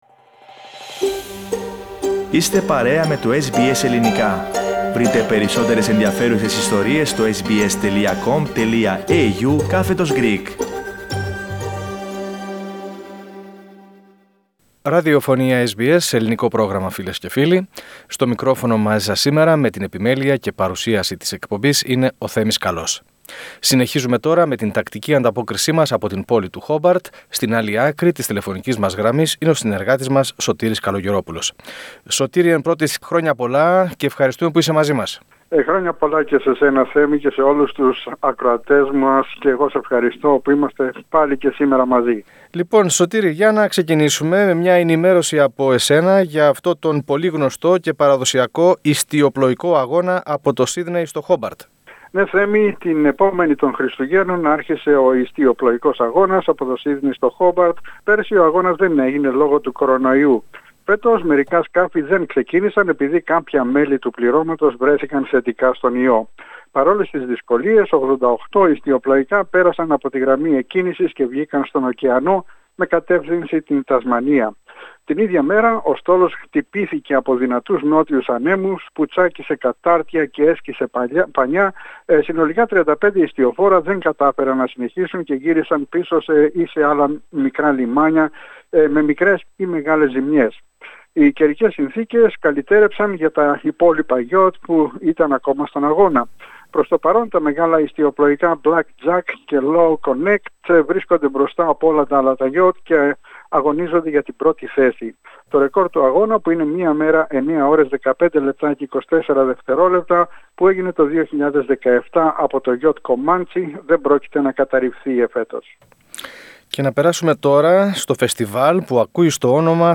Πατήστε PLAY πάνω στην εικόνα για να ακούσετε την ανταπόκριση του SBS Greek/ SBS Ελληνικά από την Τασμανία.